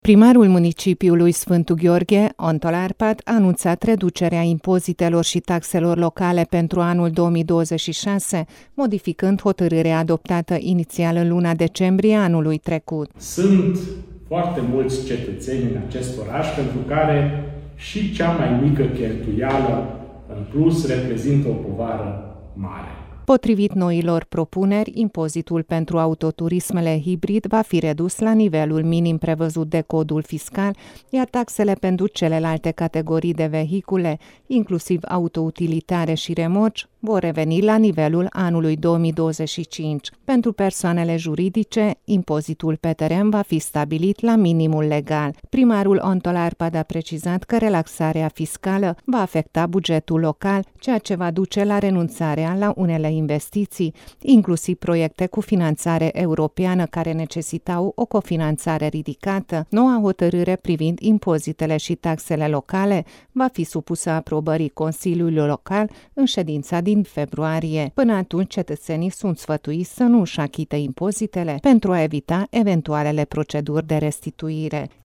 Într-o conferință de presă, alături de deputatul Miklós Zoltán, președintele UDMR Sfântu Gheorghe, primarul a subliniat că un lider trebuie să asculte vocea oamenilor și să își adapteze deciziile la realitate, mai ales în perioade dificile, când multe familii sunt preocupate de traiul de la o lună la alta.